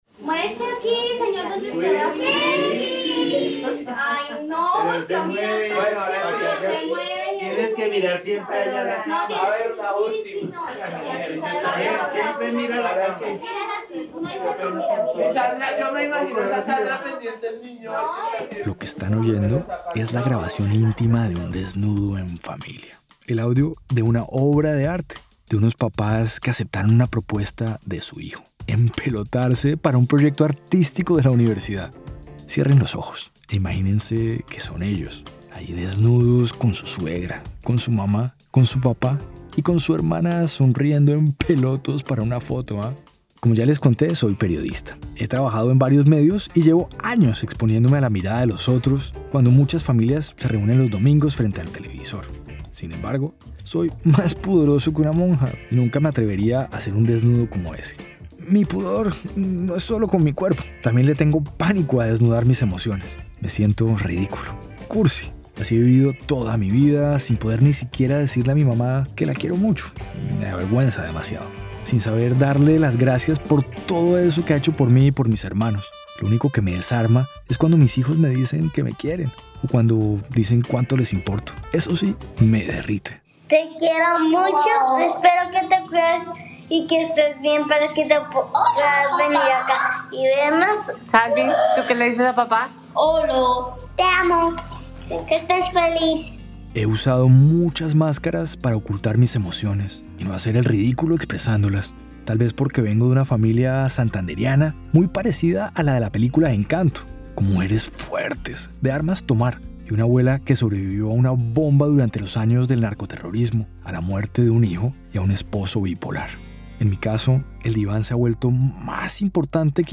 En medio de una conversación con un artista del performance, que a través del desnudo aceptó su cuerpo, su sexualidad diversa y resolvió algunos problemas familiares, un periodista pudoroso empieza a superar el miedo a hablar sobre sus sentimientos.